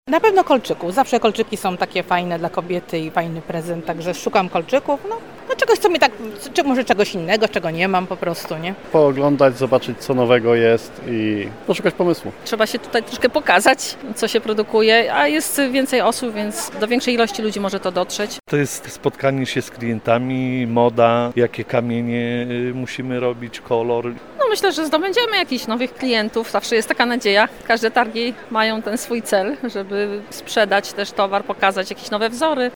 Posłuchaj, co mówią wystawcy i goście https
odwiedzaajacy-i-wystawcy-.mp3